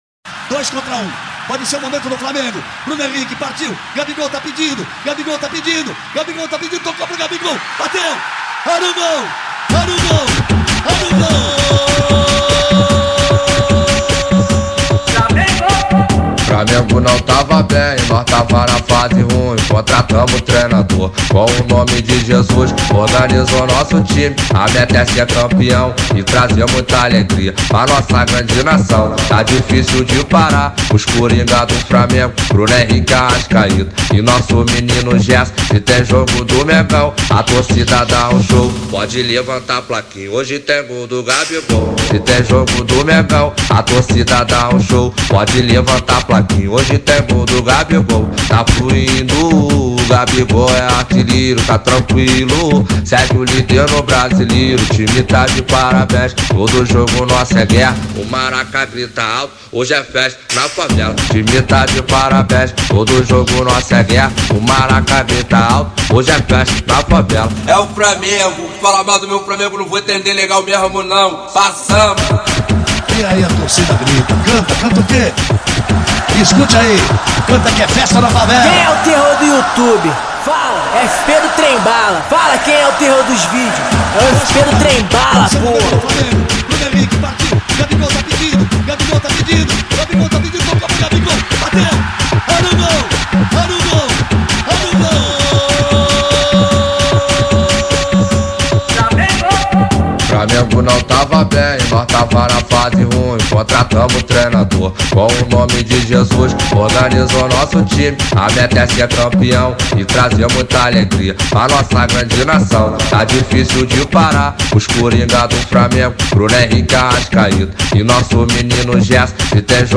2024-02-20 23:19:52 Gênero: Funk Views